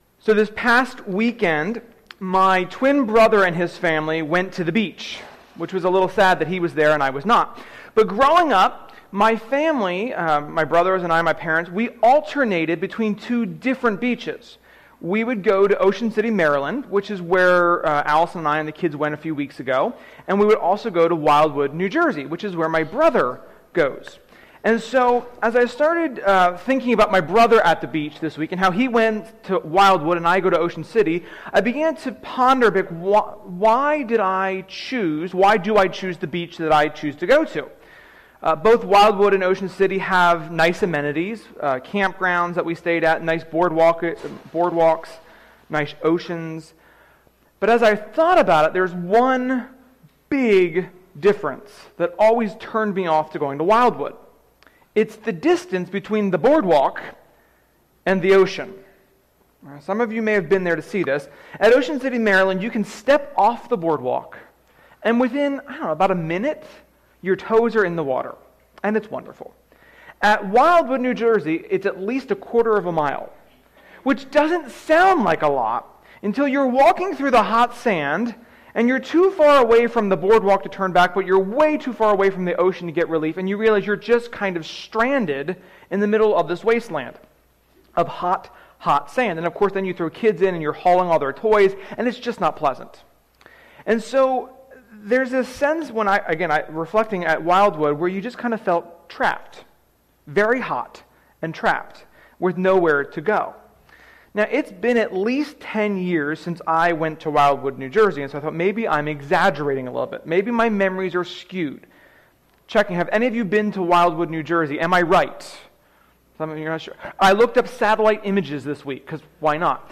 Sermon-9.19.21.mp3